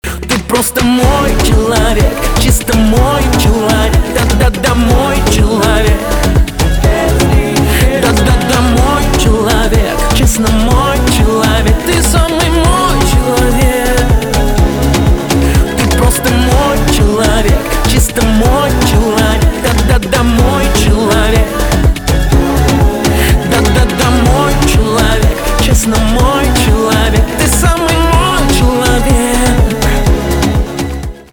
поп
романтические , чувственные , кайфовые , битовые